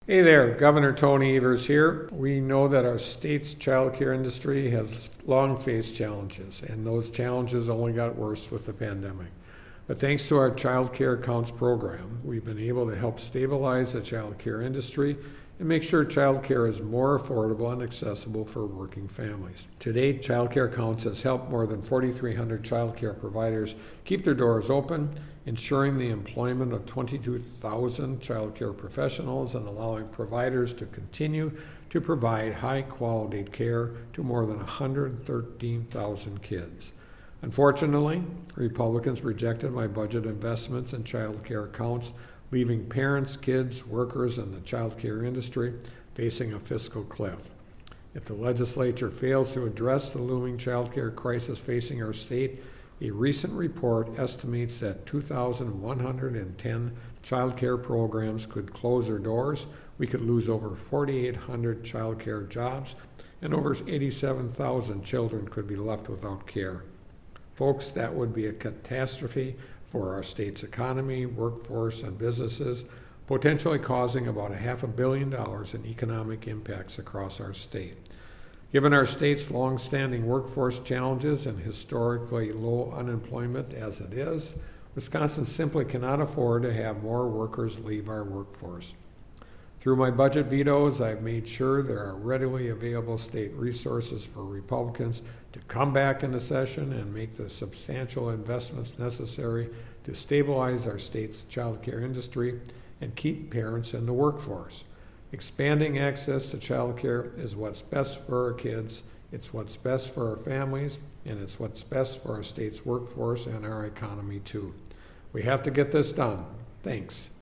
Weekly Dem radio address: Gov. Evers urges Republicans to invest in making child care more affordable, accessible statewide - WisPolitics